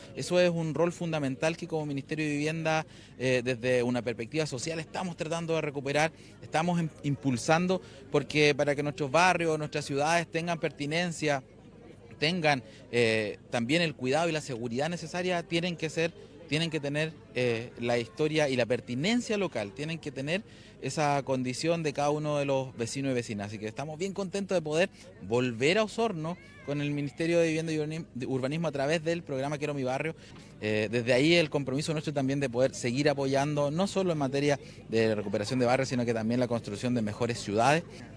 El sábado recién pasado, se realizó el hito inaugural del Programa de Recuperación de Barrios en la Población García Hurtado de Mendoza, donde se ejecuta el Programa Quiero Mi Barrio del Ministerio de Vivienda y Urbanismo, en coordinación con la Municipalidad de Osorno.
El Seremi de Vivienda y Urbanismo, Fabián Nail recalcó que este es un barrio histórico de Osorno, el que actualmente contará con una intervención que nace desde la solicitud de los vecinos y vecinas.